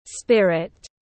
Linh hồn tiếng anh gọi là spirit, phiên âm tiếng anh đọc là /ˈspɪr.ɪt/